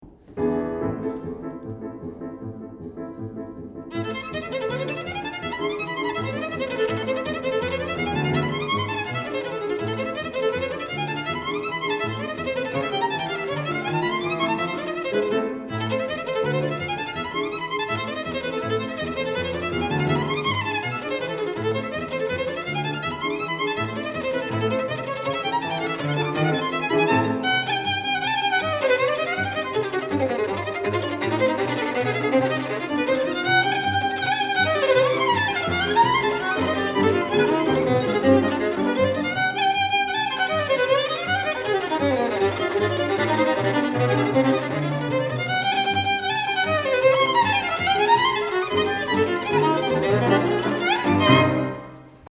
Geiger